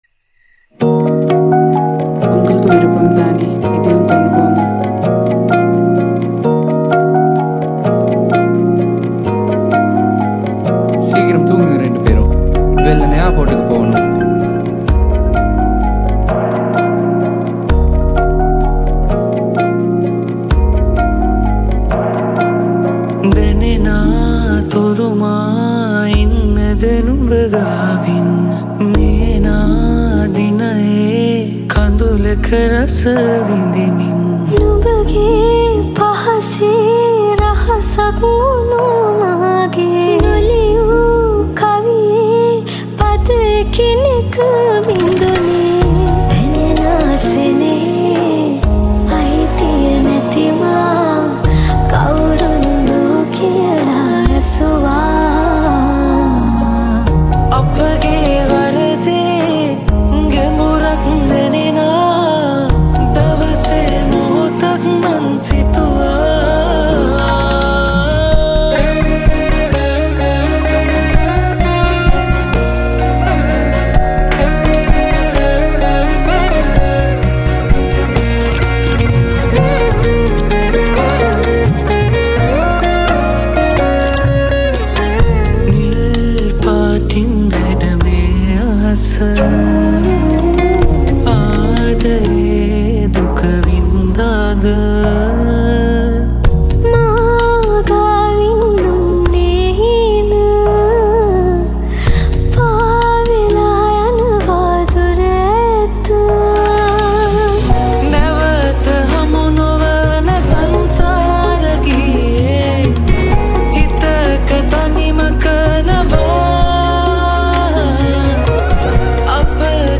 Guitar
Veena
Flute